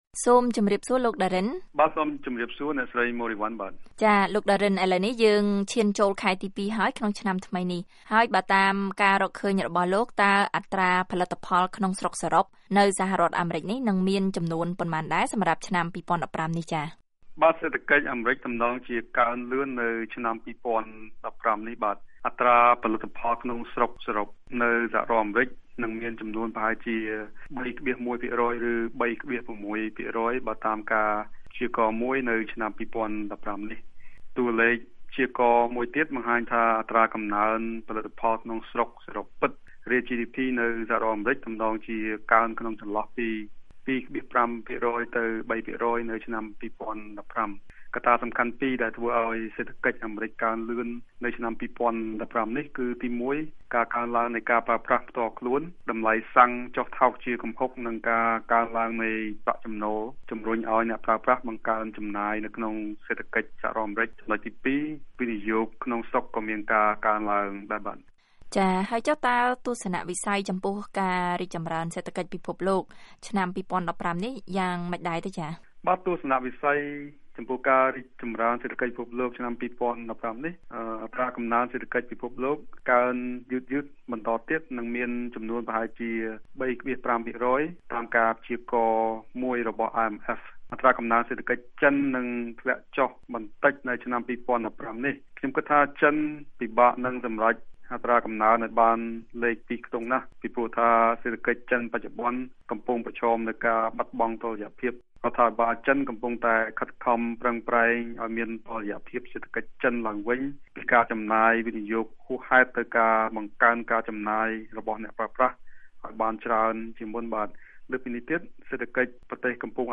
សម្រាប់ឆ្នាំ២០១៥នេះ សេដ្ឋវិទូផ្តោតជាសំខាន់ទៅលើកត្តាមួយចំនួនគឺ សេដ្ឋកិច្ចធ្លាក់ចុះនៅក្នុងតំបន់ប្រើប្រាស់ប្រាក់អ៊ឺរ៉ូ តម្លៃប្រេងធ្លាក់ចុះយ៉ាងគំហុក ការឡើងចុះតម្លៃហ៊ុននៅអាមេរិកក្នុងពេលប៉ុន្មានសប្តាហ៍ថ្មីៗនេះ និងការពង្រឹងតម្លៃប្រាក់ដុល្លារអាមេរិក។ សូមស្តាប់បទសម្ភាសន៍VOA